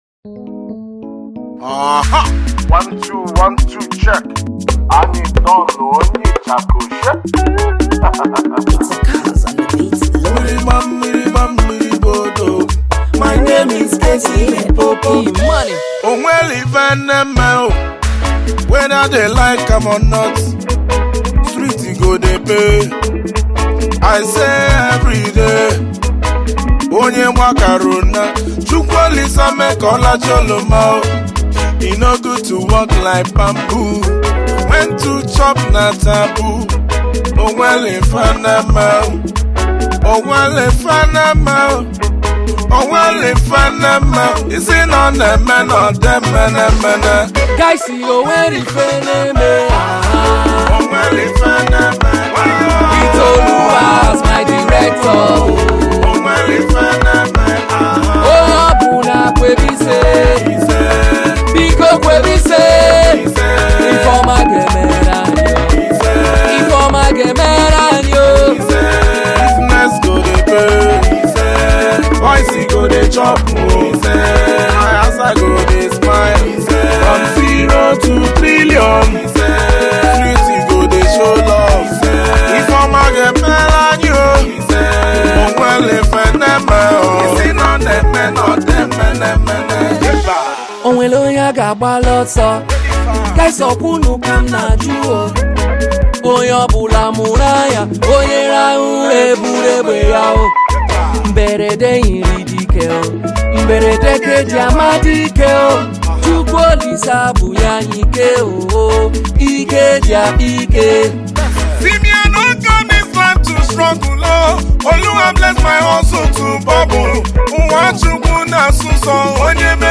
February 18, 2025 Publisher 01 Gospel 0